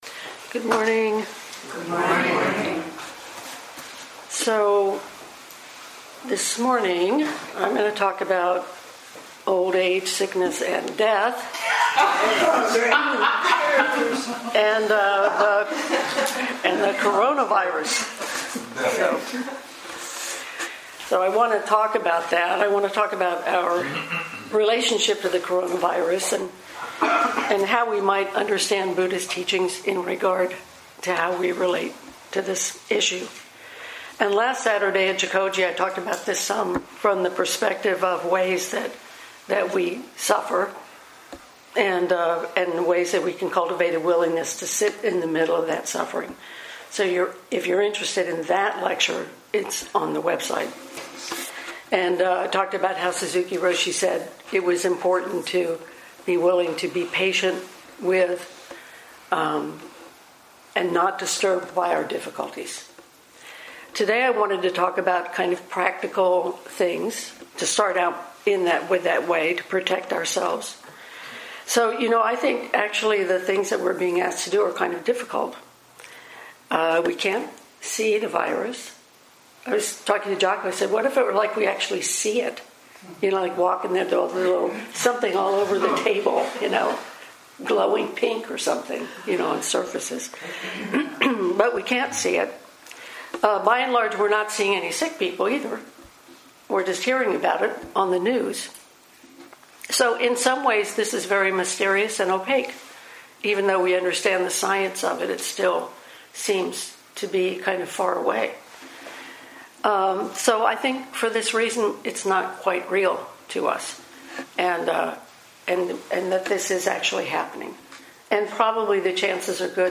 2020 in Dharma Talks